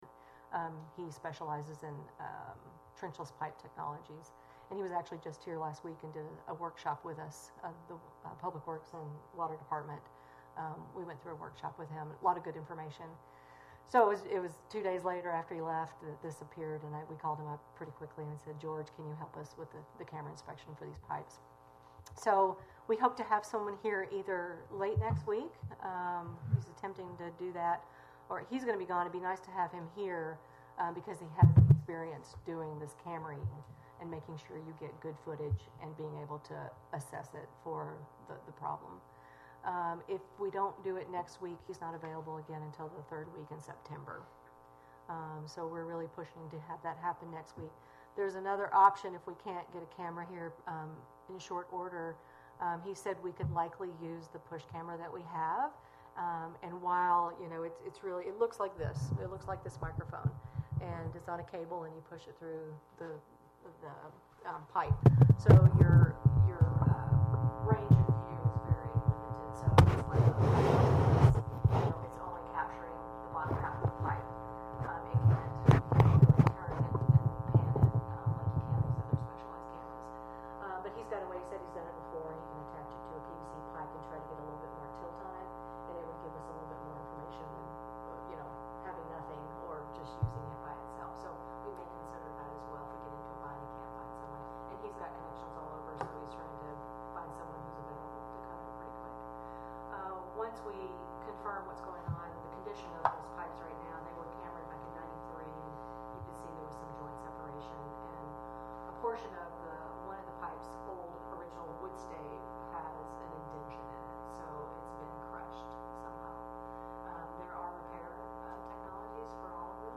Wrangell Borough Assembly met for a regular meeting on August 7, 2018.